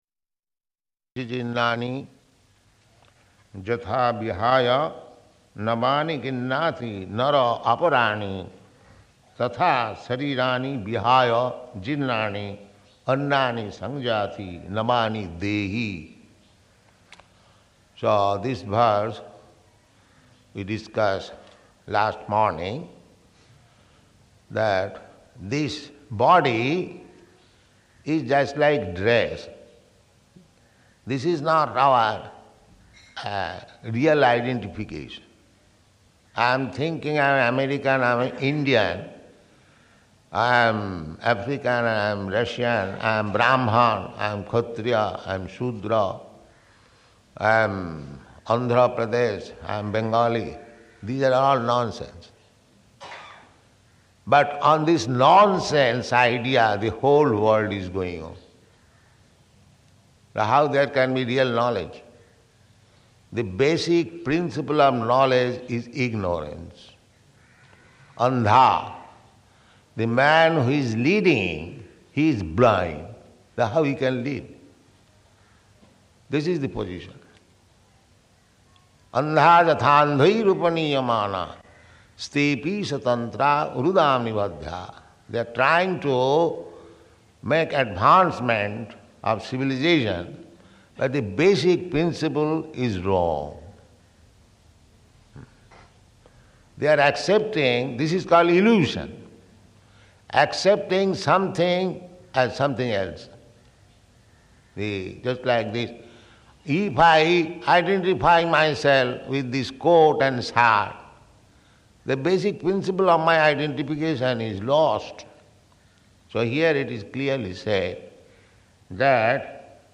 Location: Hyderabad